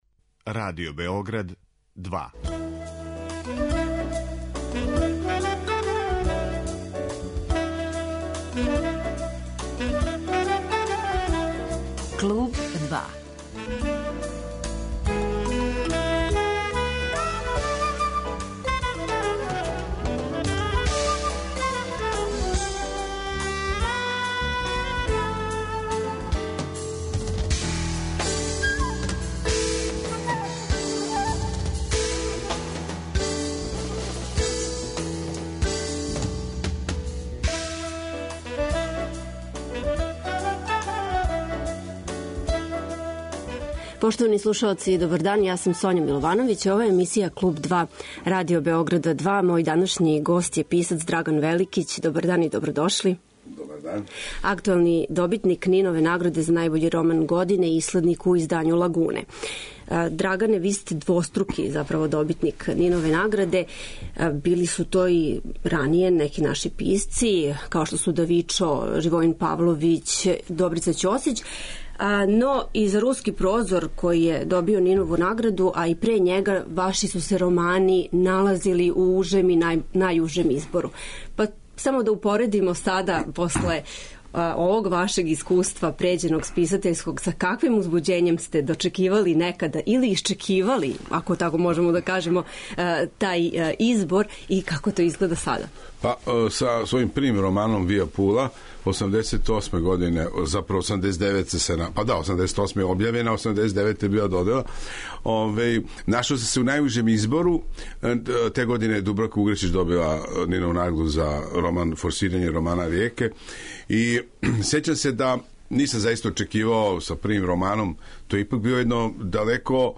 Гост 'Клуба 2' биће писац Драган Великић, актуелни добитник Нинове награде.